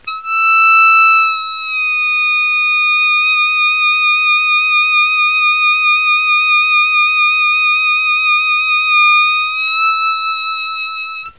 Bend8 sustain add vibrato
bend8vibrato.wav